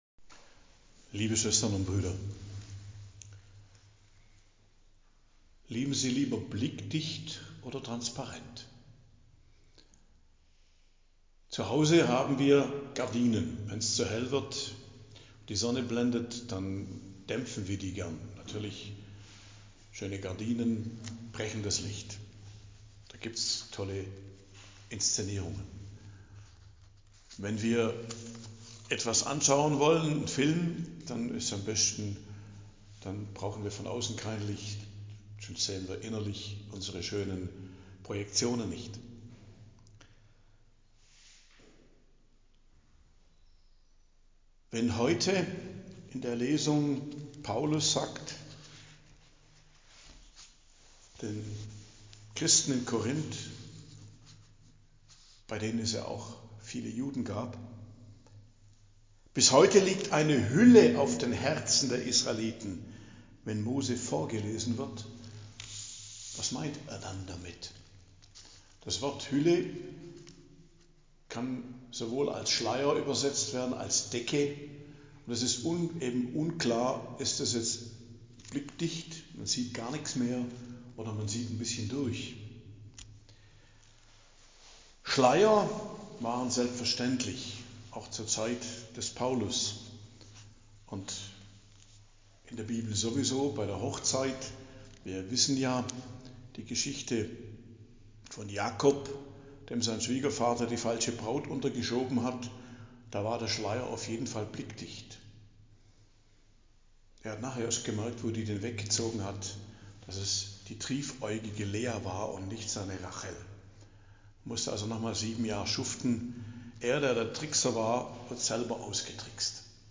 Predigt am Donnerstag der 10. Woche i.J., 12.06.2025 ~ Geistliches Zentrum Kloster Heiligkreuztal Podcast